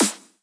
normal-hitnormal.wav